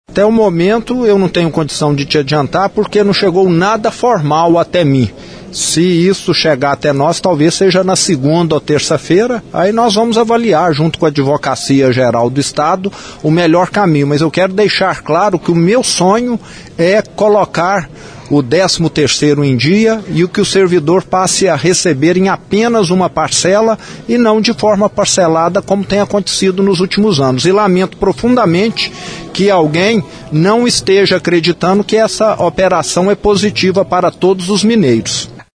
Durante o encontro, o governador concedeu entrevista a imprensa. Questionado sobre a medida cautelar do Ministério Público de Contas, para tentar suspender a operação de comercialização do nióbio, disse que não recebeu nada formal sobre o caso e, quando receber, vai analisar com a Advocacia Geral do Estado.
governador Romeu Zema